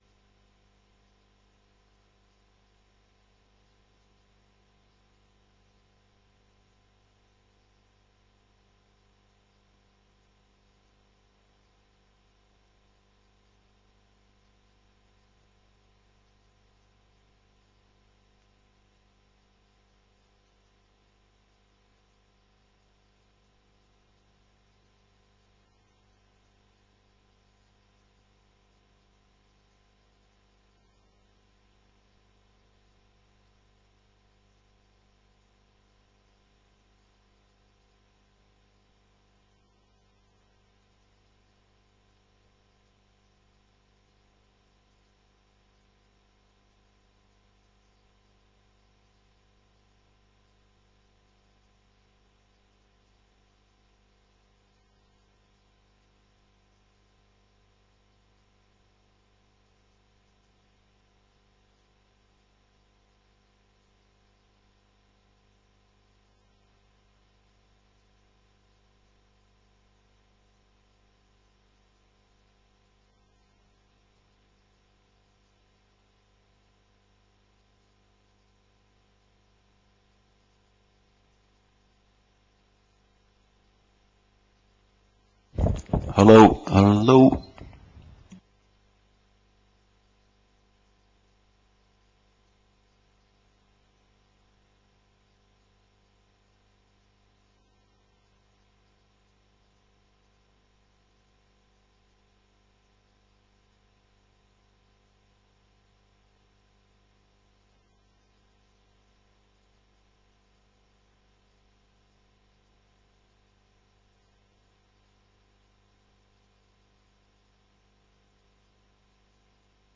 Raadscommissie 23 maart 2011 19:30:00, Gemeente Beemster
Download de volledige audio van deze vergadering
Locatie: Raadzaal